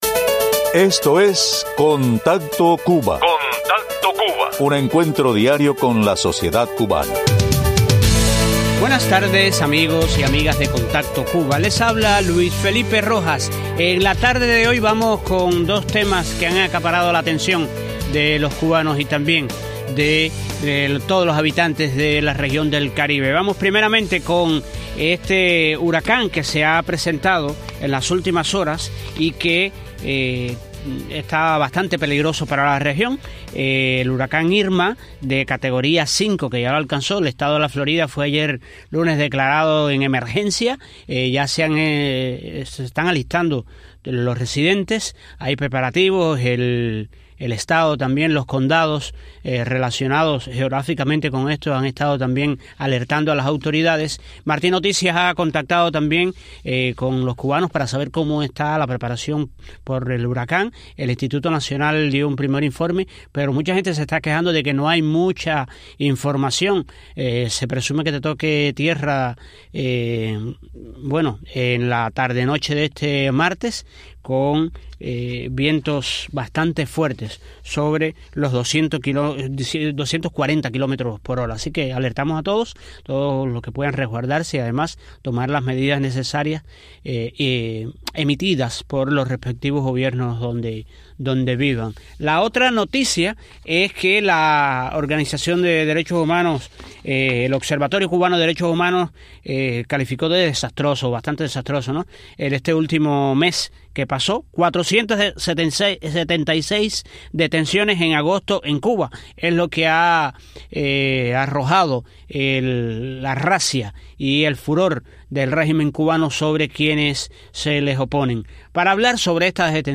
El Observatorio Cubano de Derechos Humanos denuncia que en el mes de agosto, el gobierno cubano ha realizado 476 detenciones arbitrarias. Entrevistas